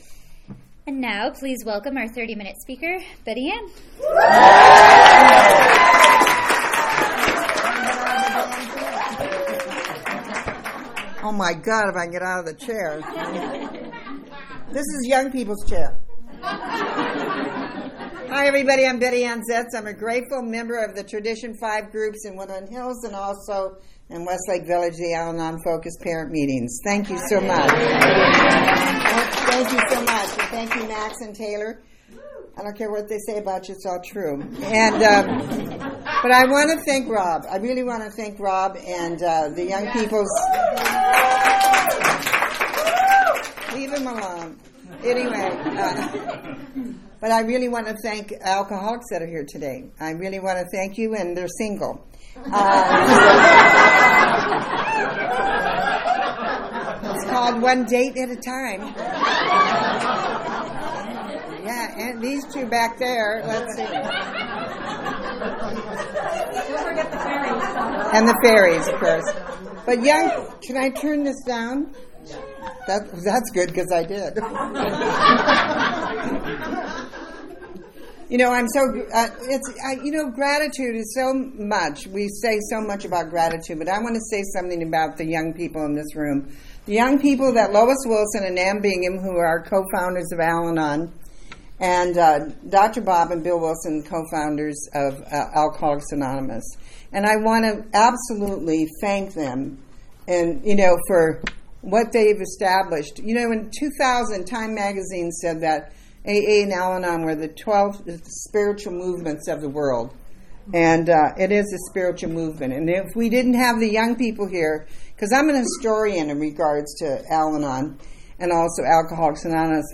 47th Annual San Fernando Valley AA Convention